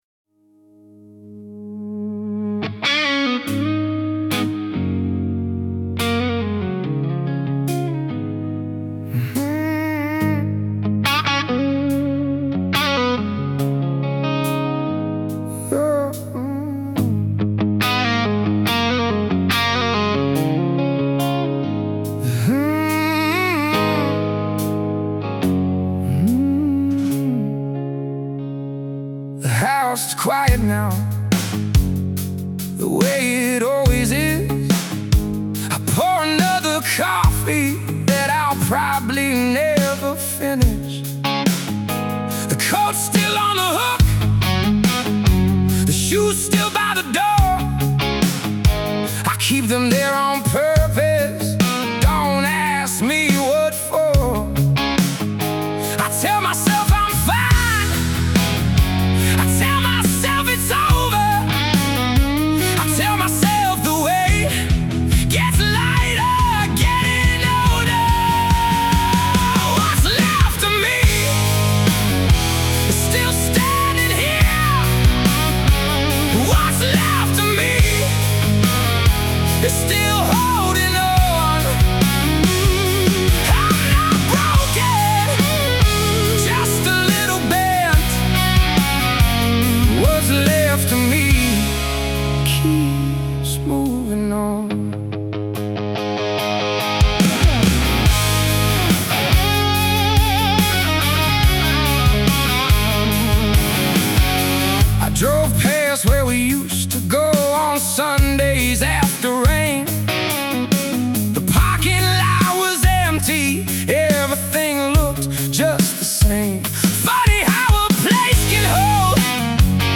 hard rock, blues rock, melancholic rock